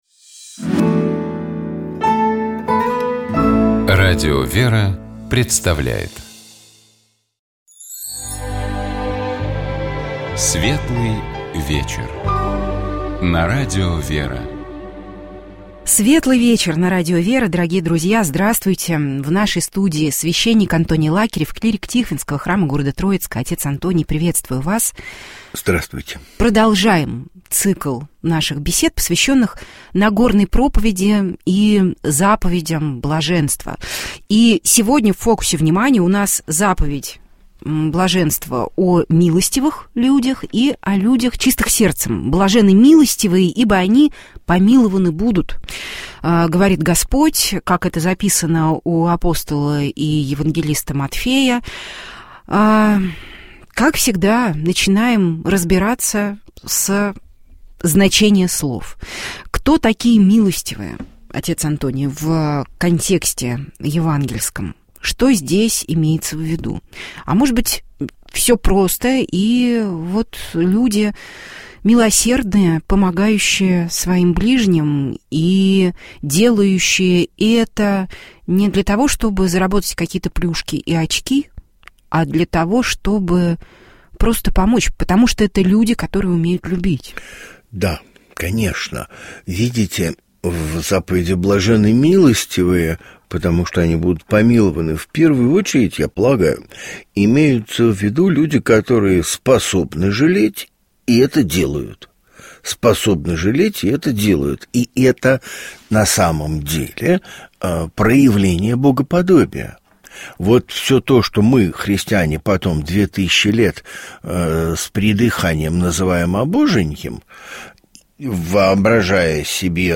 Программа «Светлый вечер» — это душевная беседа ведущих и гостей в студии Радио ВЕРА.